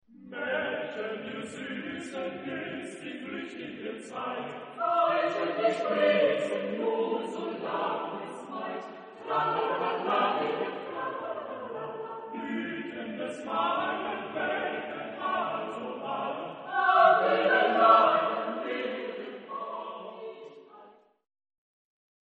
Genre-Stil-Form: Zyklus ; Chorlied ; weltlich
Chorgattung: SATB  (4 gemischter Chor Stimmen )
Tonart(en): frei